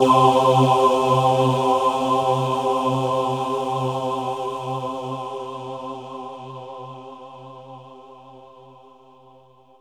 VOX_CHORAL_0006.wav